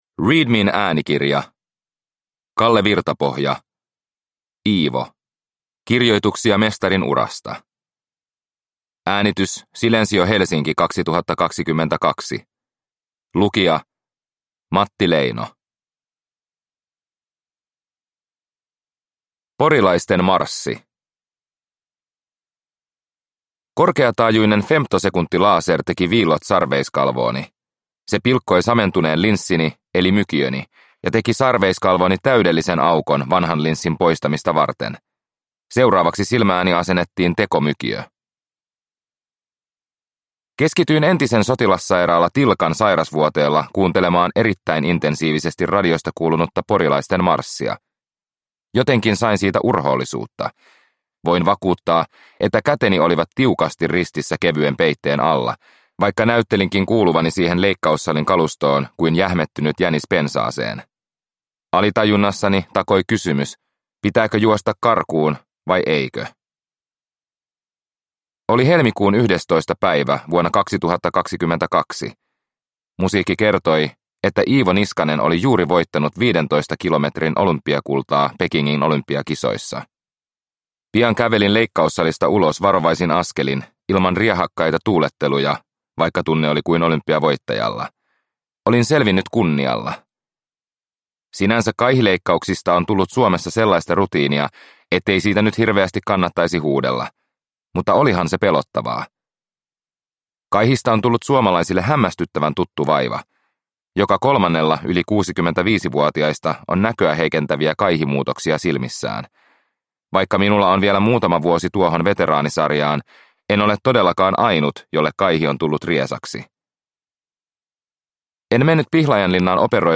Iivo - Kirjoituksia mestarin urasta – Ljudbok